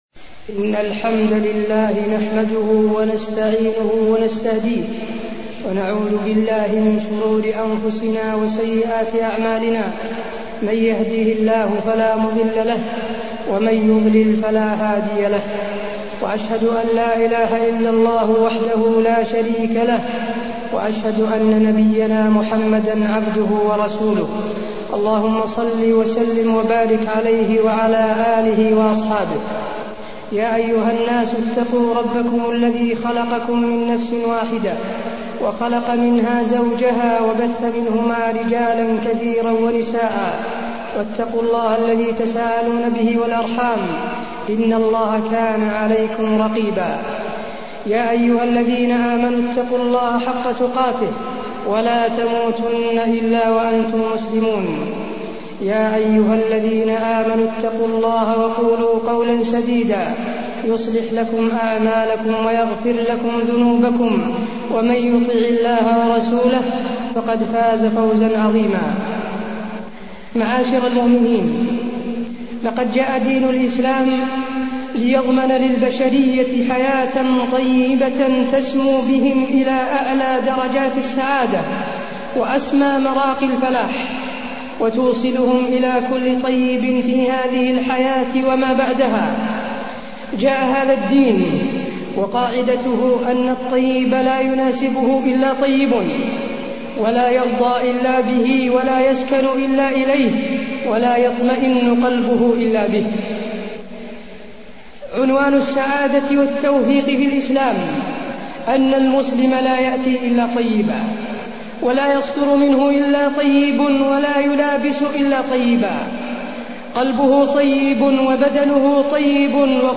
تاريخ النشر ١٧ شوال ١٤٢١ هـ المكان: المسجد النبوي الشيخ: فضيلة الشيخ د. حسين بن عبدالعزيز آل الشيخ فضيلة الشيخ د. حسين بن عبدالعزيز آل الشيخ الكسب الحلال